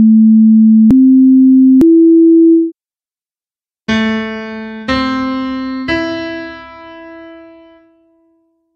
Ascolta la differenza tra le due sequenze di tre suoni: la prima è formata da toni "puri", generati da software, privi di armonici; la seconda presenta suoni di un pianoforte. tono_suono.mp3
tono_suono.mp3